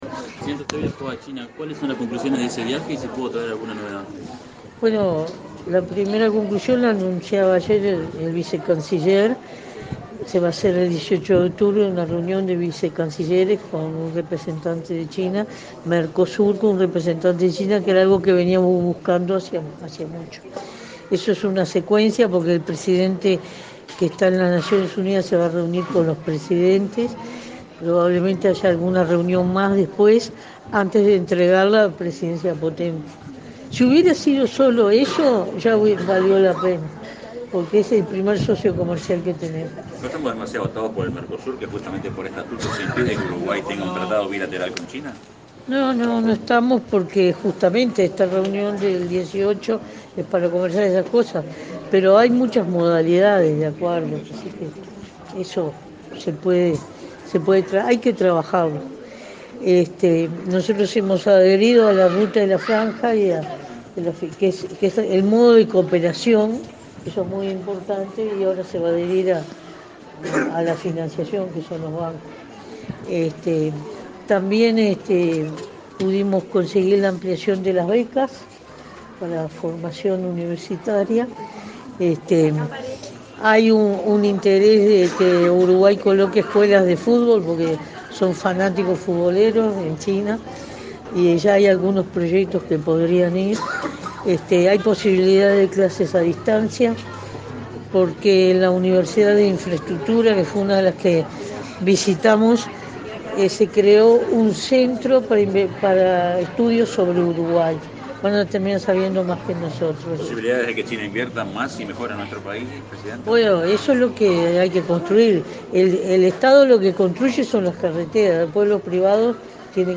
Al referirse a su reciente viaje a China, la presidenta en ejercicio, Lucía Topolansky, destacó la reunión que tendrán el 18 de octubre los cancilleres del Mercosur con el representante chino y el próximo viaje de la ministra Carolina Cosse para profundizar las relaciones bilaterales y trabajar en futuras inversiones en software y la colocación de productos de pymes. Dijo que se creará un centro de estudios sobre Uruguay.